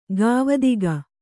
♪ gāvadiga